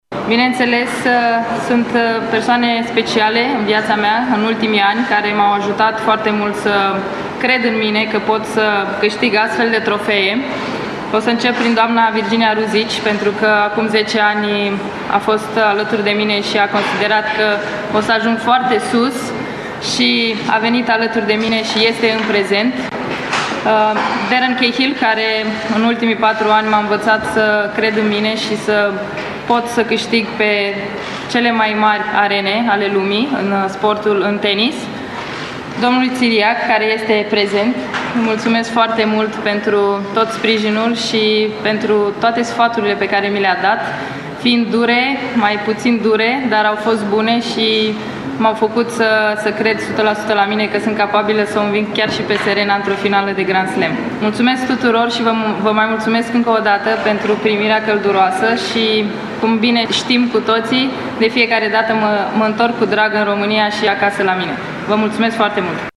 Simona Halep s-a întors, luni după-amiază, în țară, după victoria de la Wimbledon.
Într-un scurt discurs, Simona Halep a ținut să mulțumească familiei și oficialilor din tenis care au susținut-o în carieră.